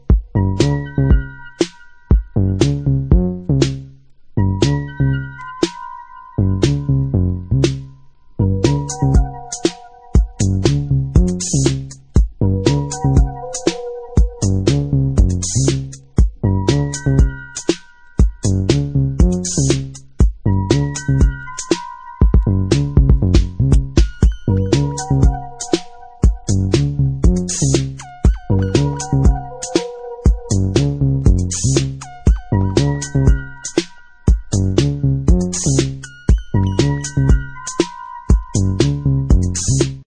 True minimal electro soundtracks